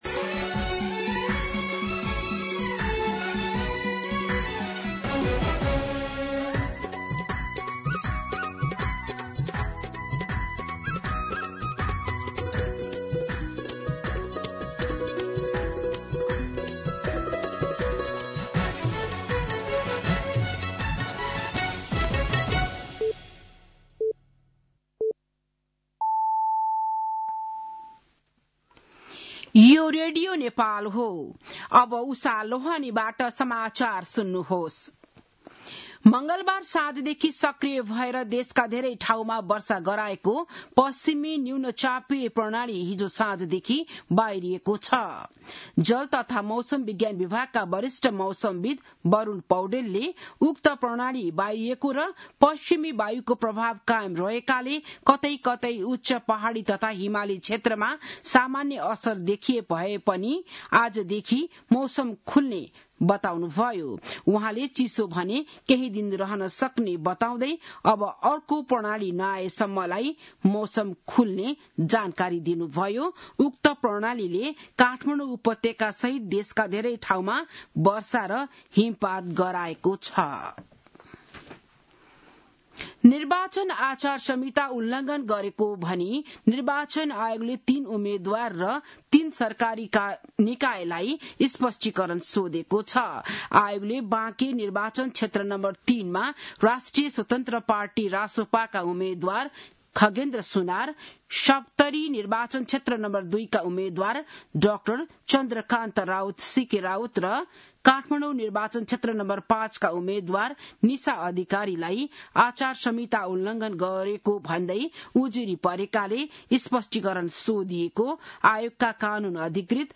An online outlet of Nepal's national radio broadcaster
बिहान ११ बजेको नेपाली समाचार : १५ माघ , २०८२